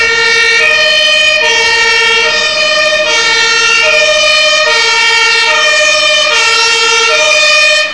Martinshörner können verschieden klingen.
Martinshorn
martinshorn.wav